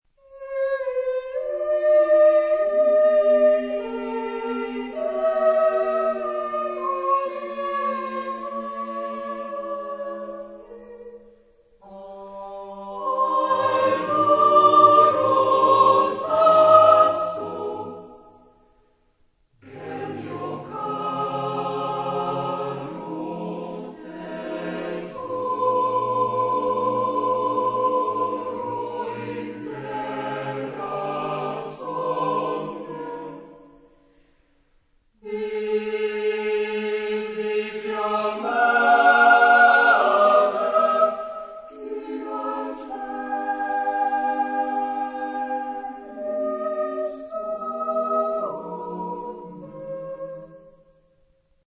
Genre-Stil-Form: weltlich ; lyrisch ; Sonett
Chorgattung: SATB  (4 gemischter Chor Stimmen )
Tonart(en): tonal